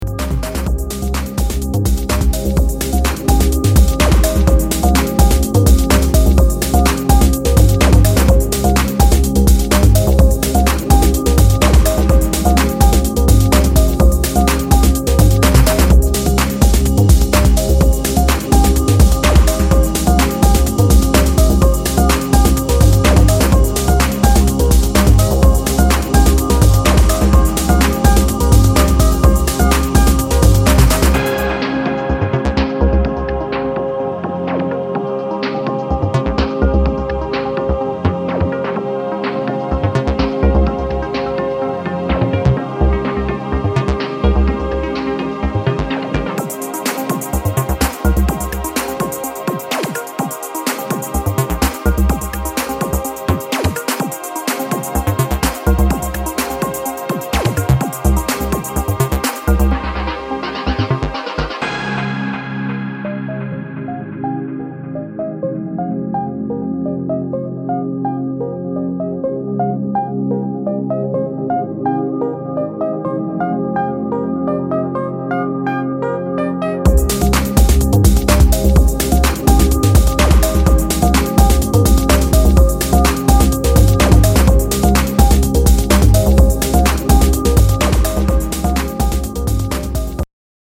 bustling and bumping House EP